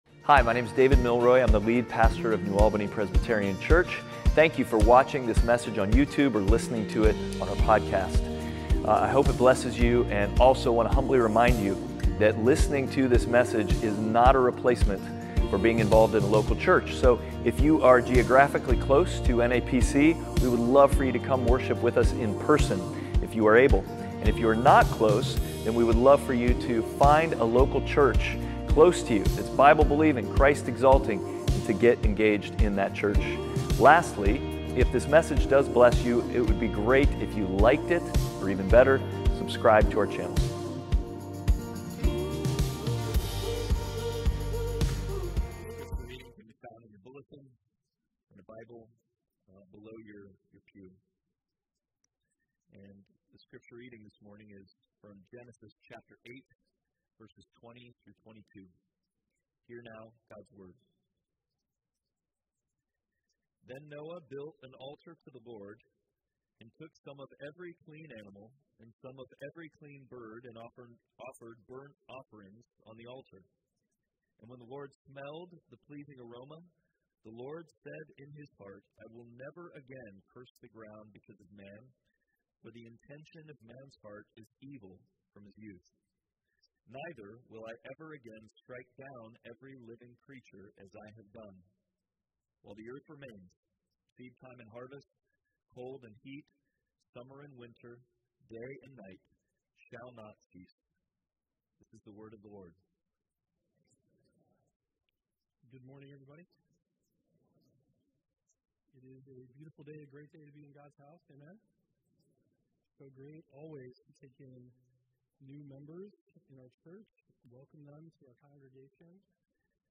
Passage: Genesis 8:20-22 Service Type: Sunday Worship